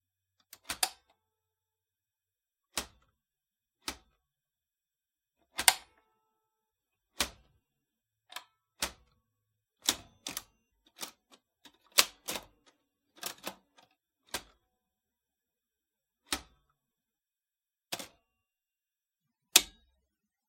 复古打字机的声音 " 单一打字机的声音
描述：处理打字机上不打字母的按钮。
Tag: 打字机 类型 处理 机械 字母 按钮 葡萄酒 经典